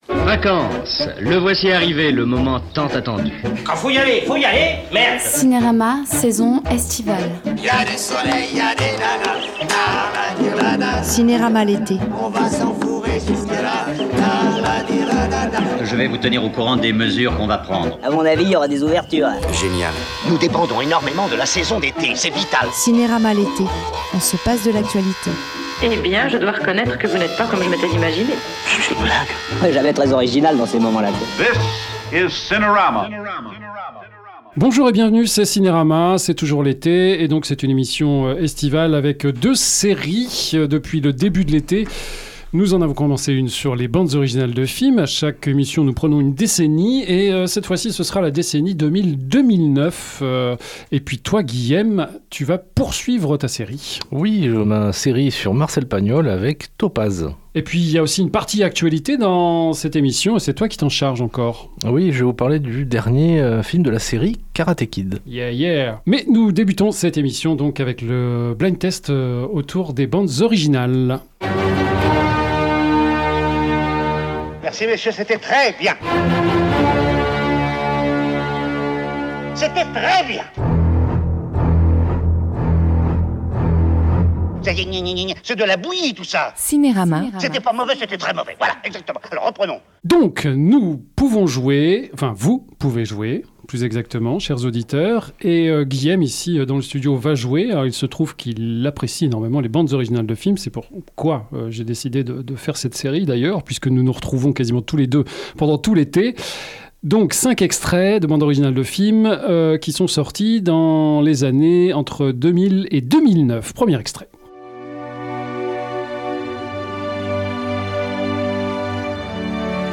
LES SÉRIES DE L’ÉTÉOn joue à deviner des bandes originales de films de la décennie 1980-1989.On en apprend davantage sur Marcel Pagnol avec le film inachevé La prière aux étoiles.